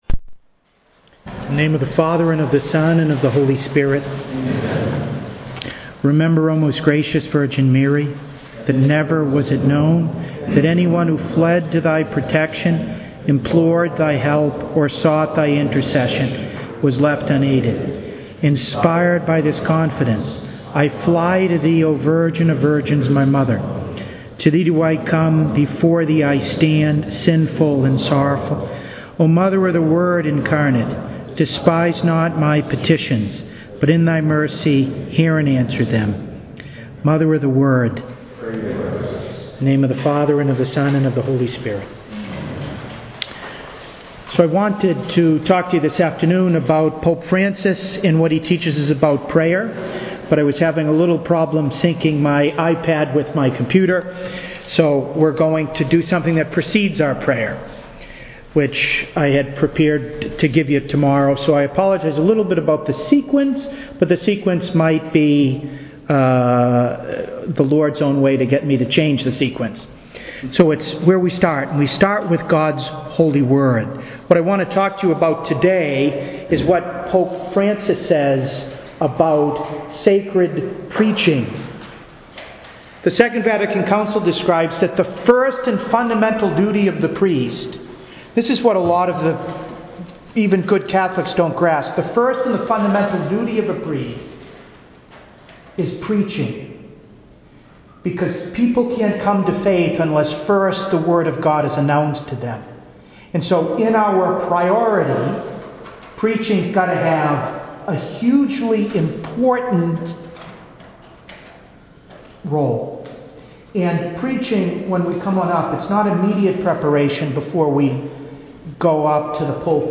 To listen to an audio recording of this conference, please click here: